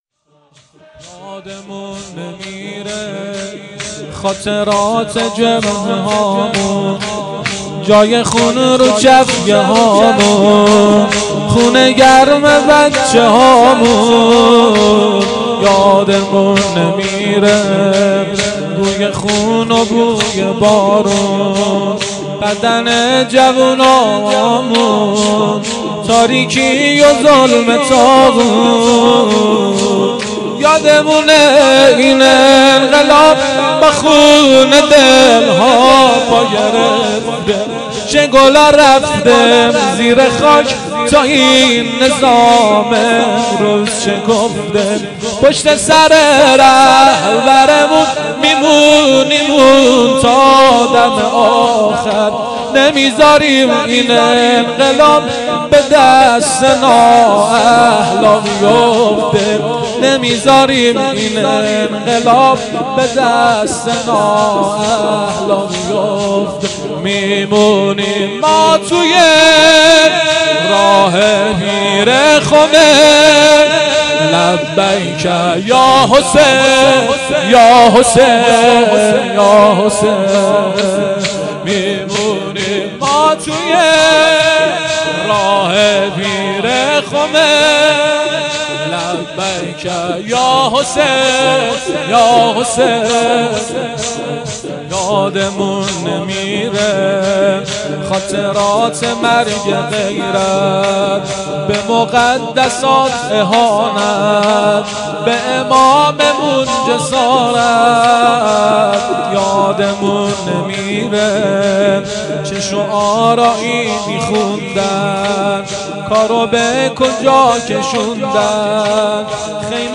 شور شهدایی